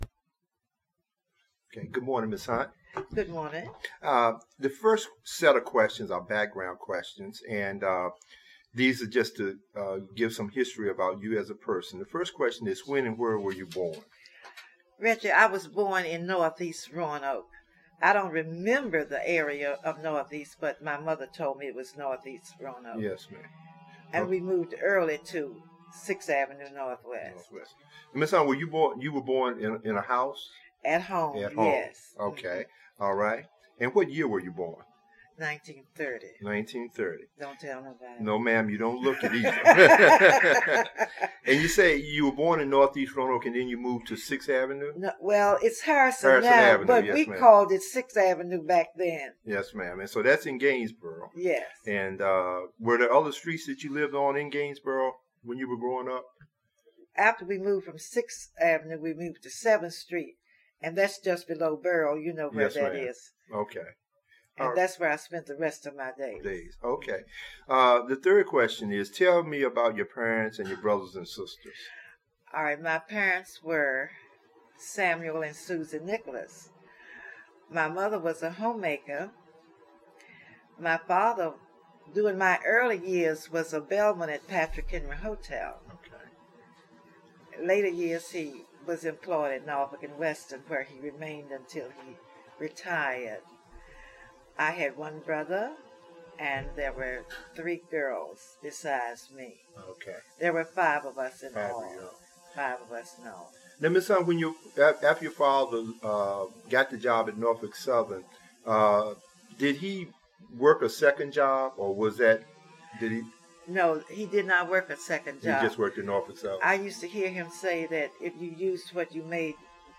Neighborhood History Interview
Location: Gainsboro Branch Library